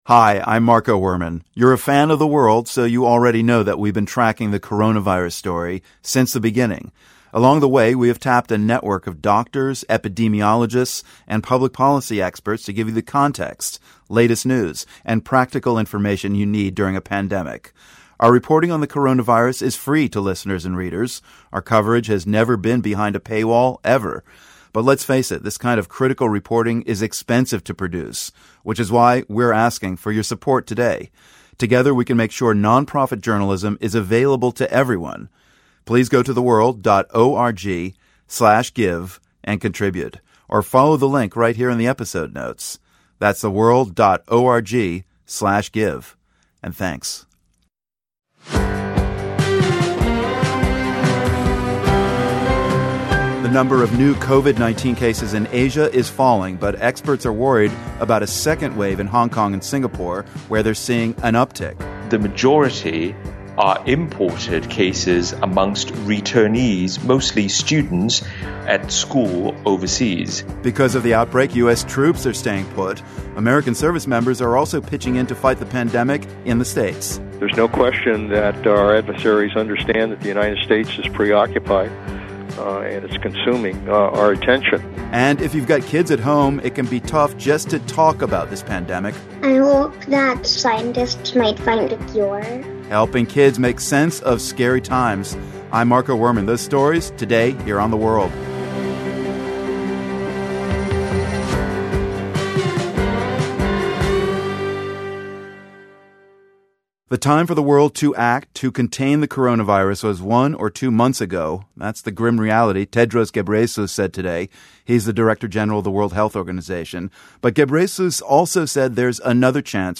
And, we speak with former Pentagon chief Leon Panetta about the challenges that limit the movement and role of US troops to help combat the coronavirus crisis. Also, how do you explain a pandemic to kids so they get the gravity of the situation, but don't freak out?